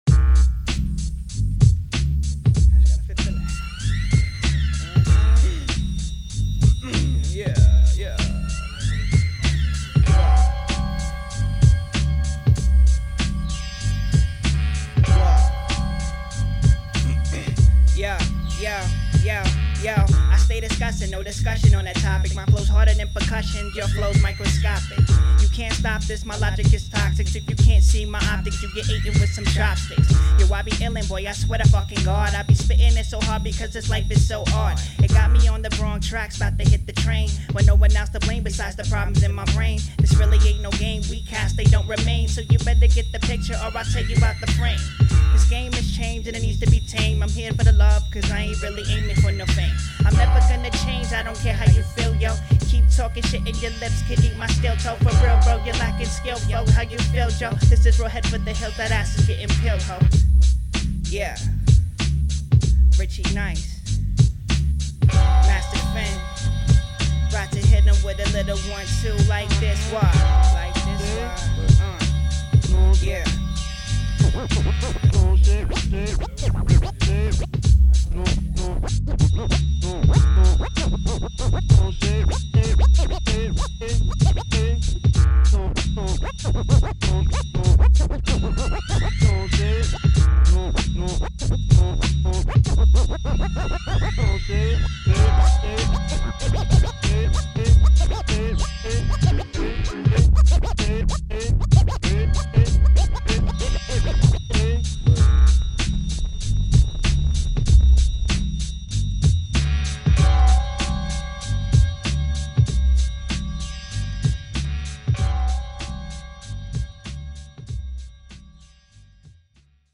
Genre: Hip Hop
Vocal Type Rap